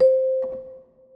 Piano - Toybox.wav